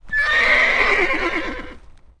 骑士的马战吼
啾啾